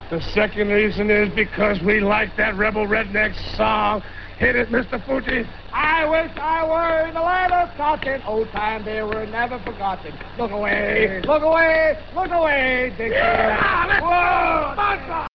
Prior to the bout, an interview was conducted with Muraco and his manager, the evil Mr. Fuji.